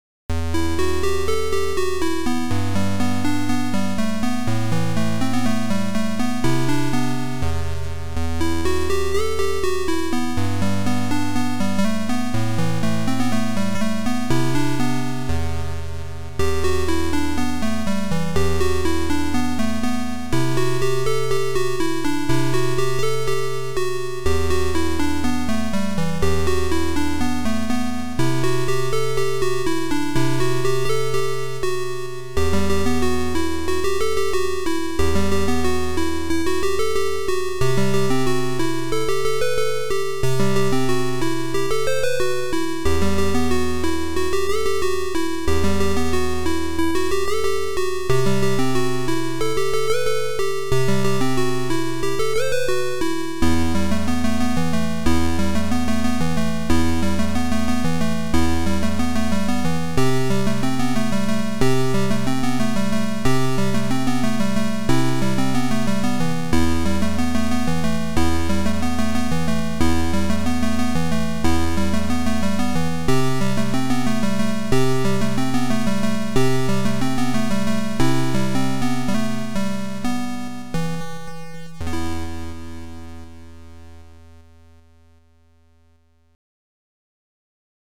AY mono